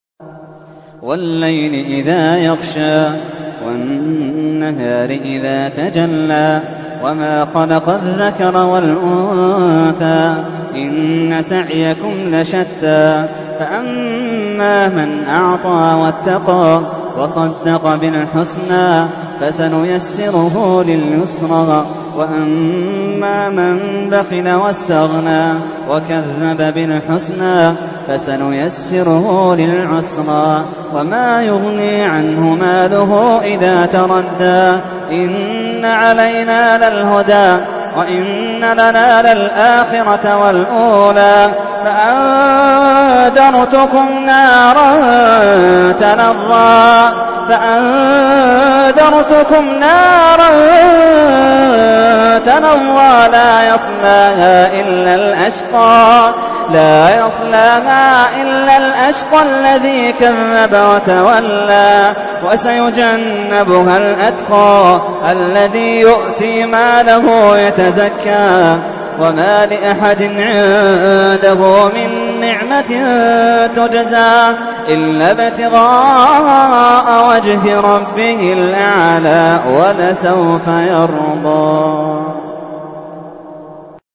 تحميل : 92. سورة الليل / القارئ محمد اللحيدان / القرآن الكريم / موقع يا حسين